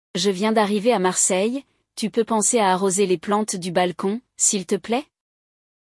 No episódio desta semana, vamos ouvir um diálogo entre um casal: um deles está viajando para Marseille e pede para o outro regar as plantas, mas parece que eles também vão celebrar algo.